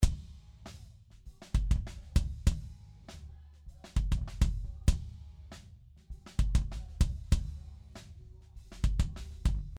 キック（イン＆アウト）、スネア（トップ＆ボトム）、タムに関しては、ライブセッションにも関わらず他の楽器のカブりが少ない。
キックの中（イン）に立てたマイク（未処理）
キックのアタックを担うインマイクですが、パワフルなドラマーによるスネアのカブりも目立ちます。
01_Lewitt-Mix-Contest_kickBypass.mp3